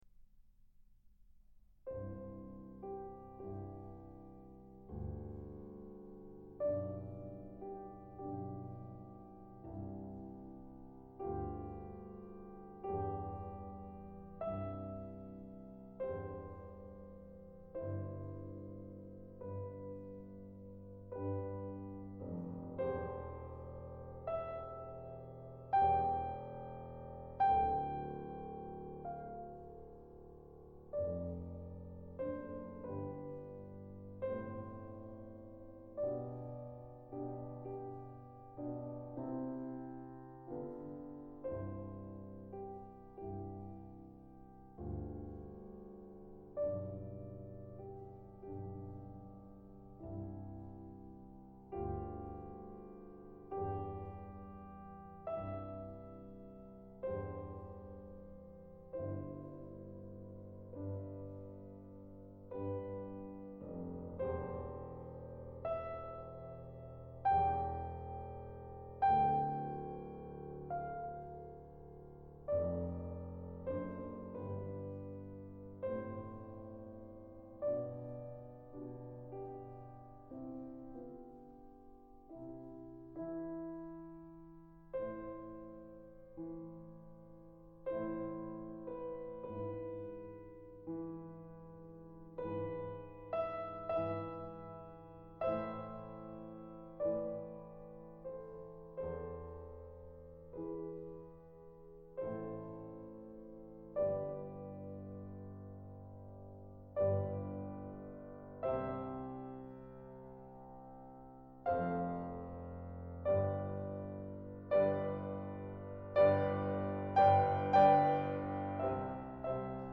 Not a respectable sound.